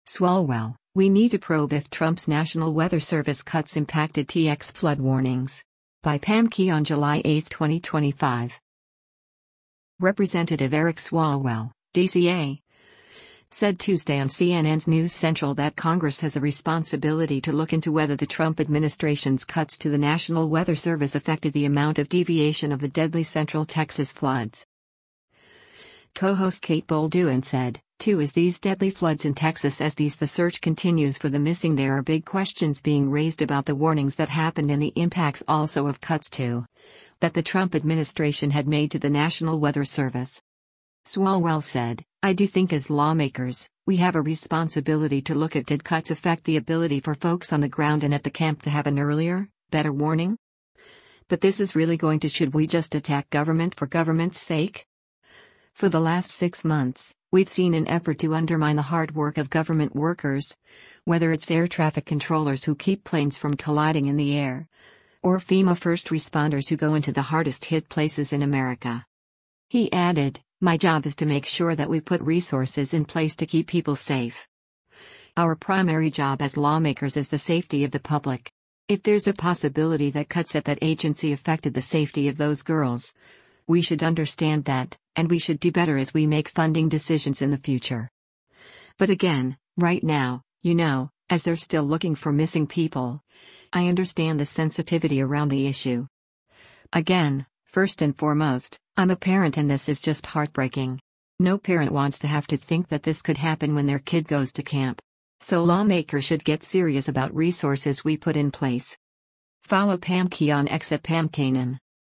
Representative Eric Swalwell (D-CA) said Tuesday on CNN’s “News Central” that Congress has a “responsibility” to look into whether the Trump administration’s cuts to the National Weather Service affected the amount of deviation of the deadly central Texas floods.